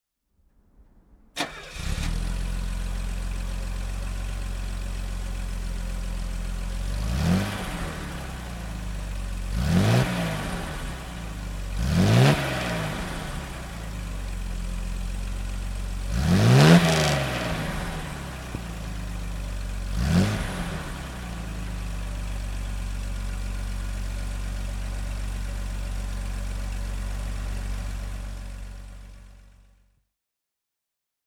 Mercedes-Benz 280 E (1981) - Starten und Leerlauf